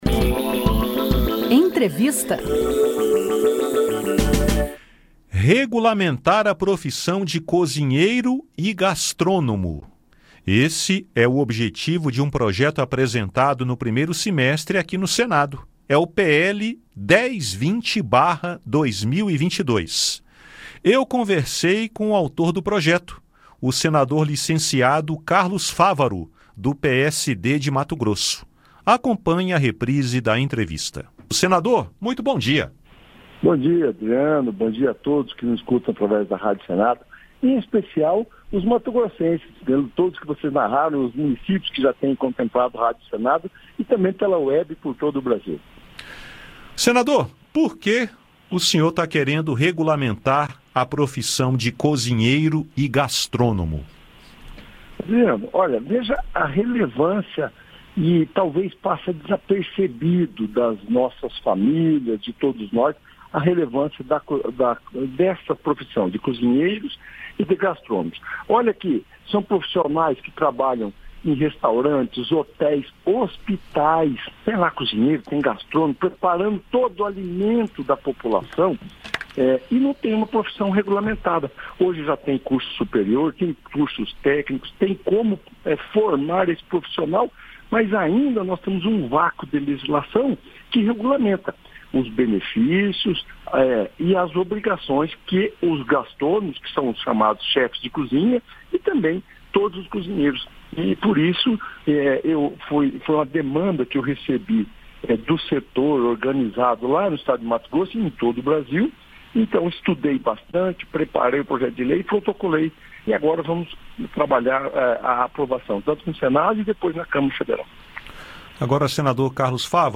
Acompanhe a reprise da entrevista.